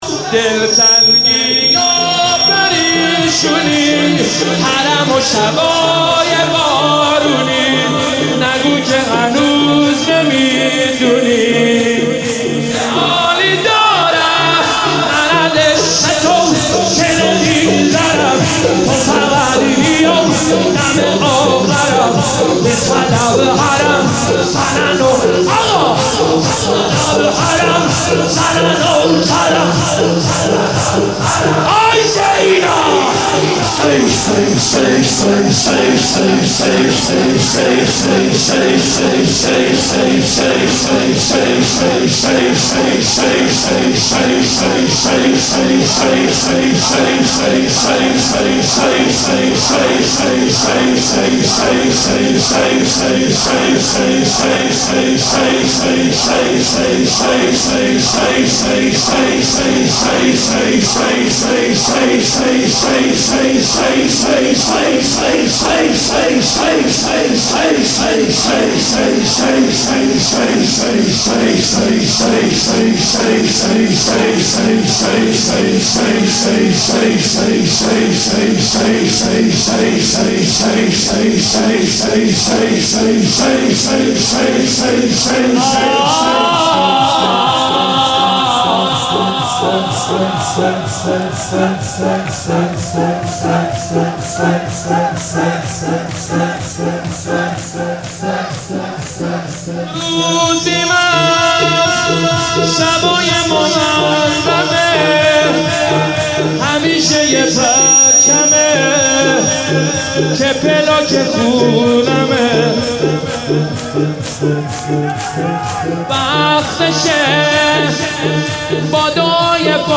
روضه شب اول
ذکر/شور